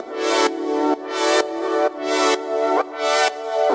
Index of /musicradar/french-house-chillout-samples/128bpm/Instruments
FHC_Pad A_128-E.wav